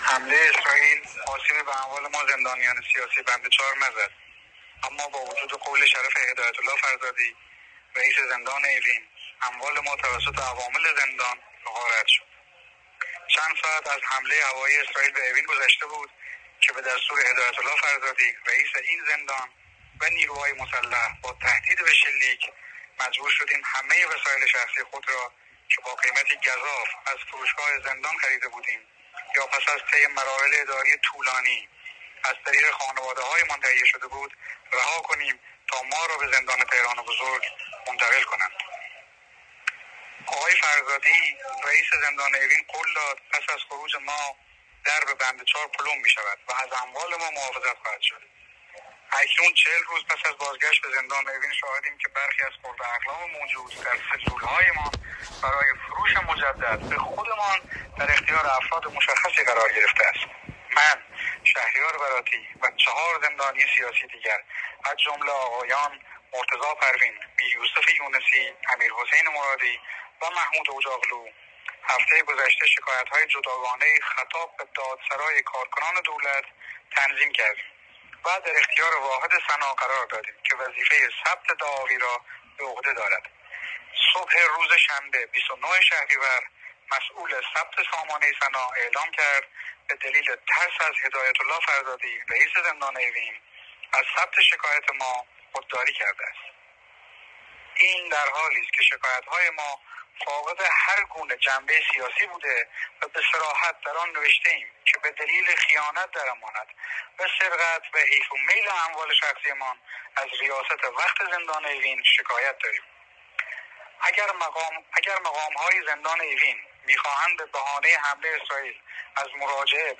در پیام صوتی که نسخه‌ای از آن به صدای آمریکا رسیده